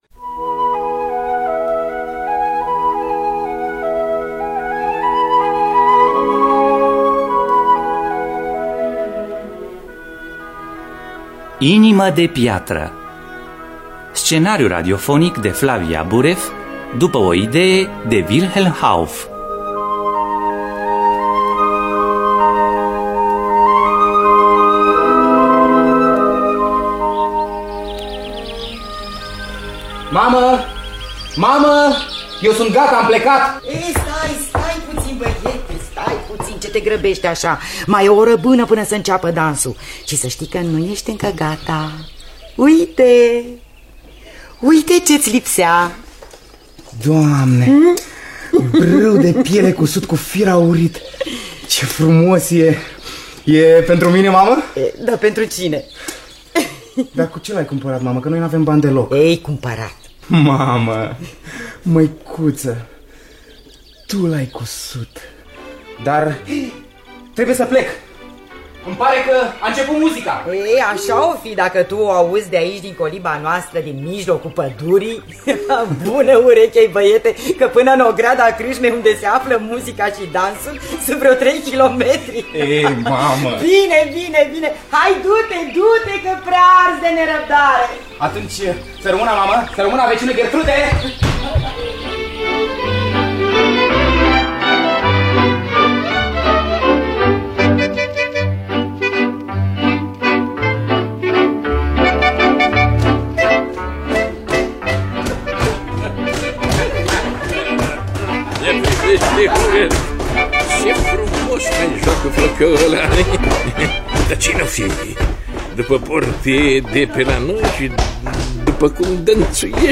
Wilhelm Hauff – Inima De Piatra (1995) – Teatru Radiofonic Online